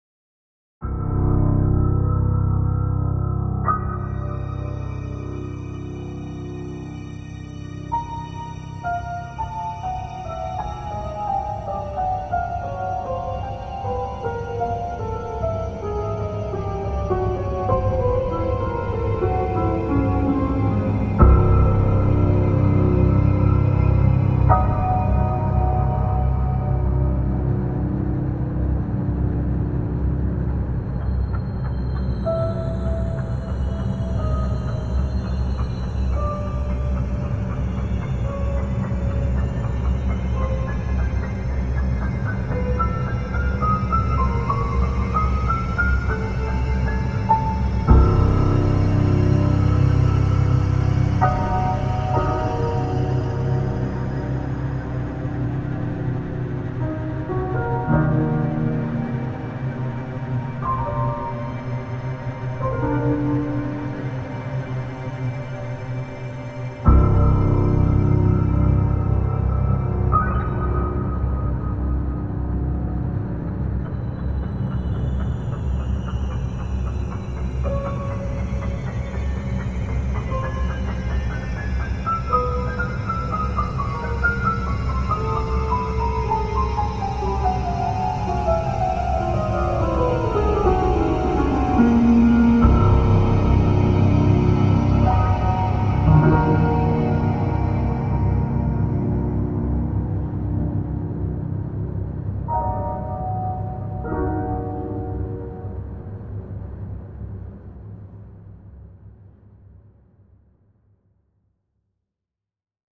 Risiko merinding di luar tanggung jawab Hipwee Premium ya~